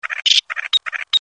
En cliquant ici vous entendrez le chant du Faucon crécerelle.